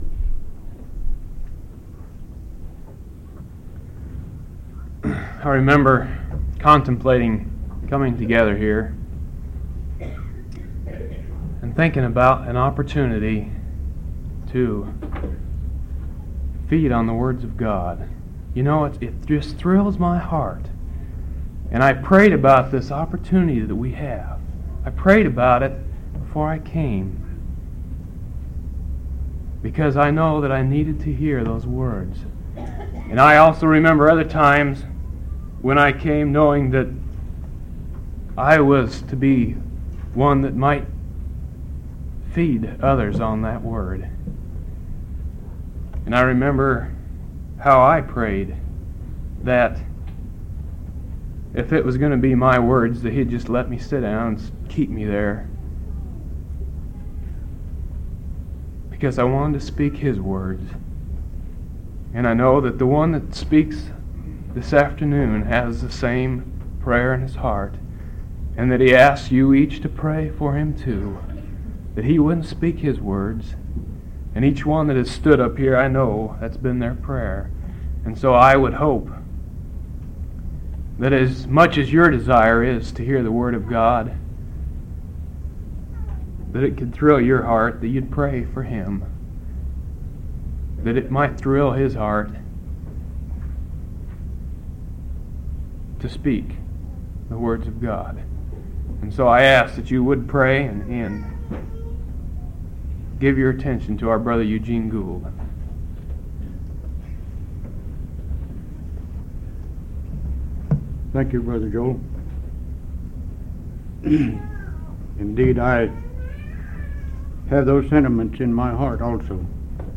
8/15/1987 Location: Missouri Reunion Event: Missouri Reunion